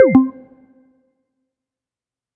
Game Start
Perfect for 8bit, beep, chiptune.
8bit beep chiptune game gui menu select start sound effect free sound royalty free Sound Effects